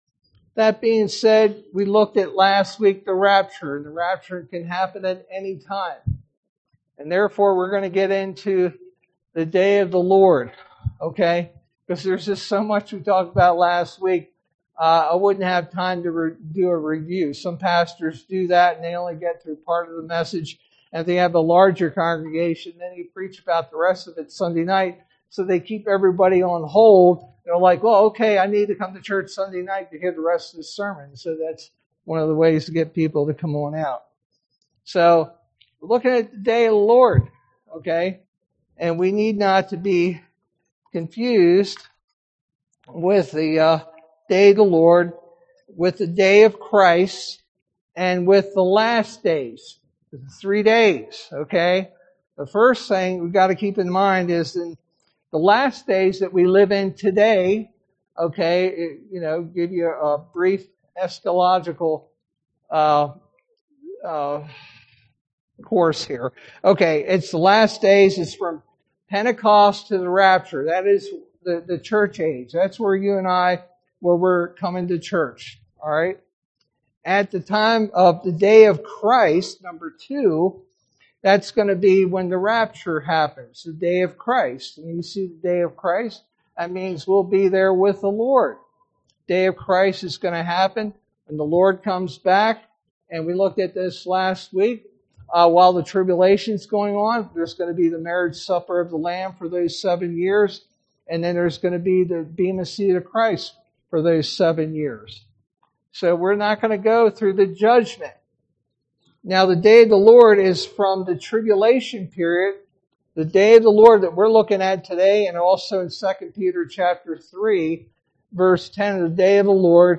sermon verse: 1 Thessalonians 5:1-11